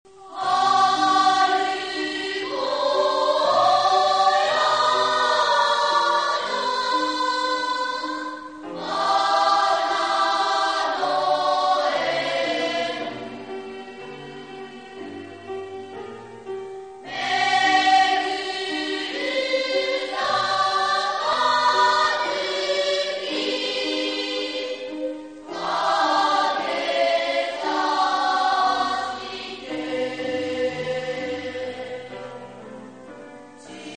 学級全員による合唱です。
聞かせどころだけを録音しました。
もとは体育館でステレオ録音したものです。
荒城の月 ２部合唱 土井晩翠 滝廉太郎 あります